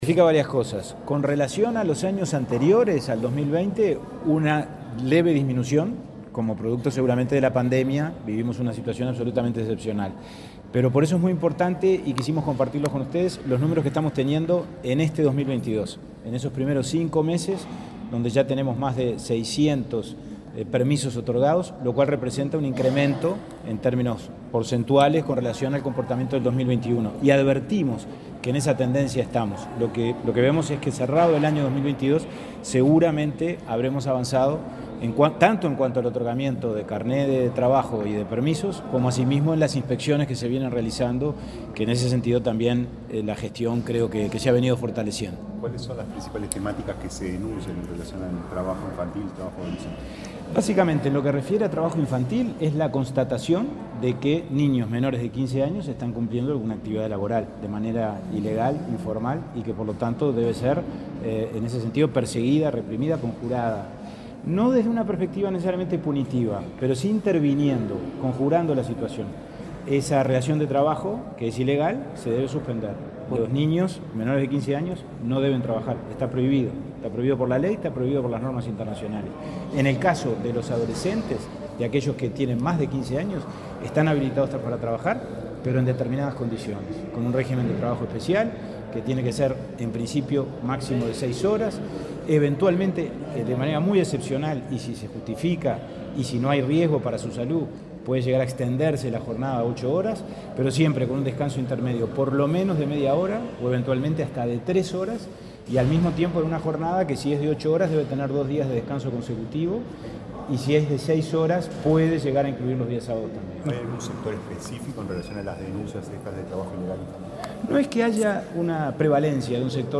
Declaraciones del presidente del INAU, Pablo Abdala
Declaraciones del presidente del INAU, Pablo Abdala 09/06/2022 Compartir Facebook X Copiar enlace WhatsApp LinkedIn El Instituto del Niño y Adolescente del Uruguay (INAU) presentó cifras sobre trabajo infantil y adolescente. En ese marco, el presidente del organismo, Pablo Abdala, dialogó con la prensa sobre esta problemática.